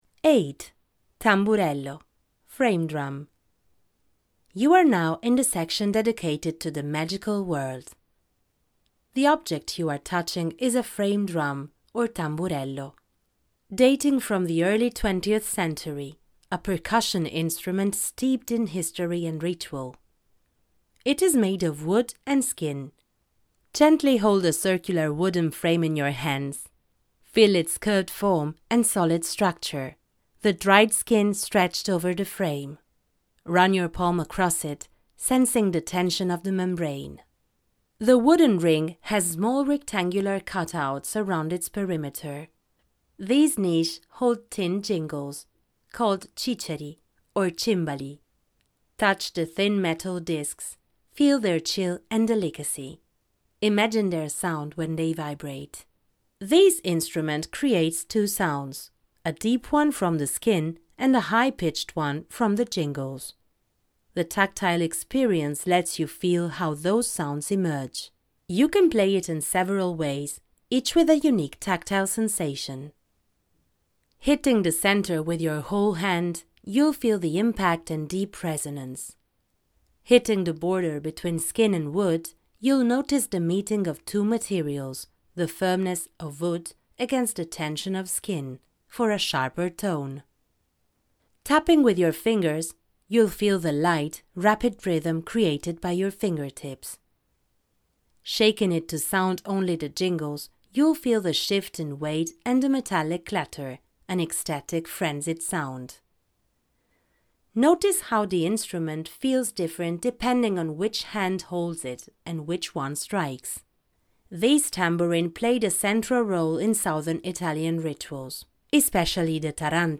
The tambourine - MUCIV-Museo delle Civiltà
02_08_TAMBURELLO-1.mp3